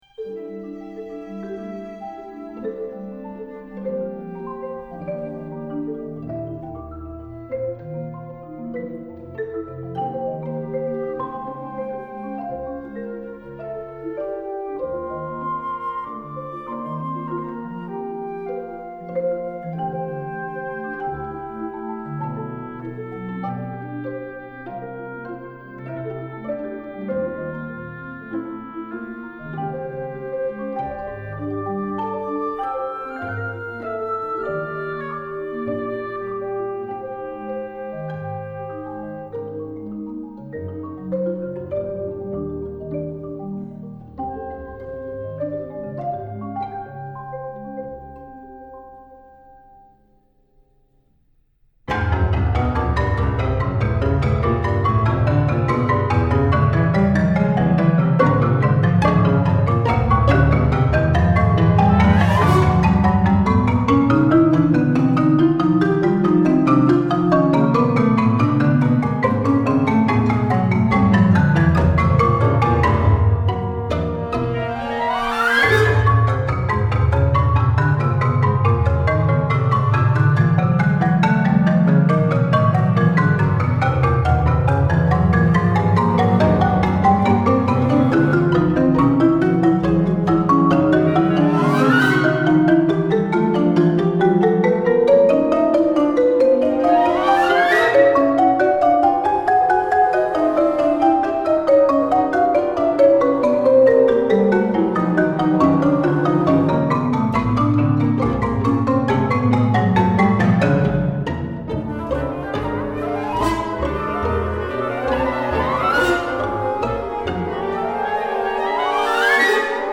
For marimba* and chamber ensemble